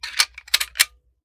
7Mag Bolt Action Rifle - Loading Rounds 003.wav